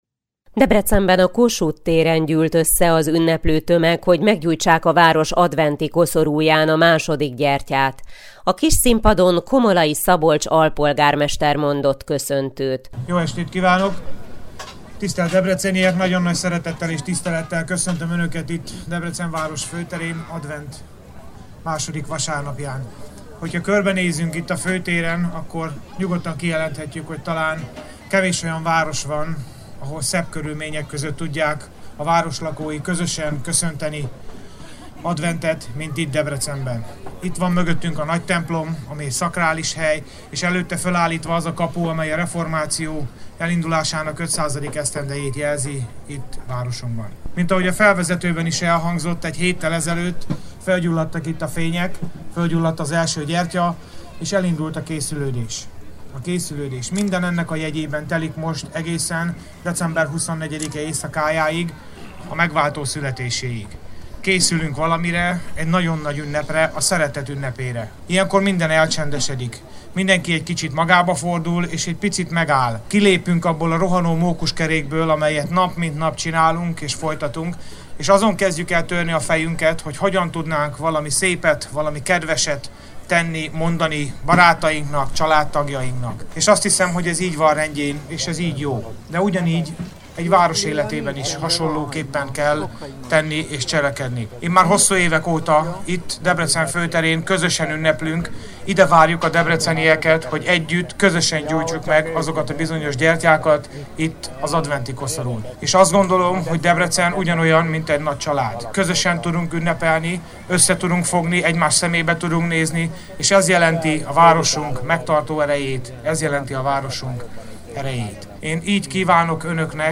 Találkozás a szeretet Istenével - második adventi gyertyagyújtás – hanganyaggal